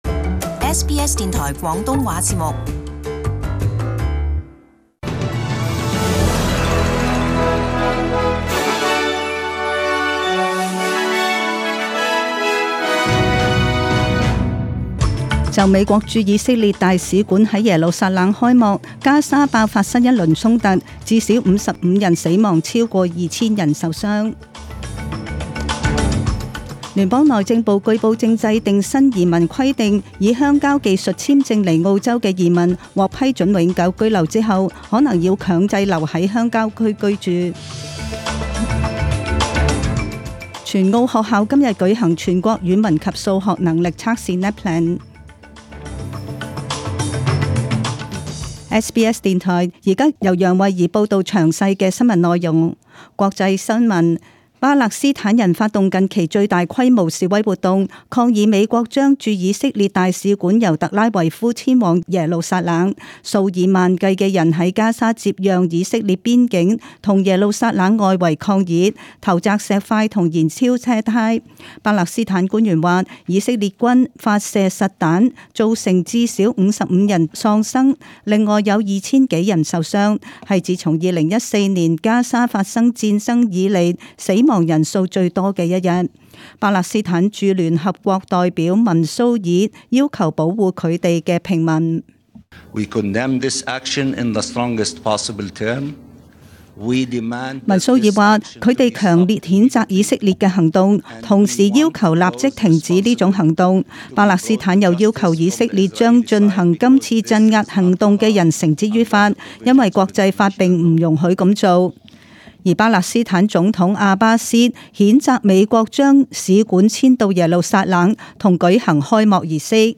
Detailed morning news bulletin.